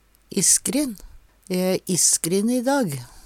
isskrin - Numedalsmål (en-US)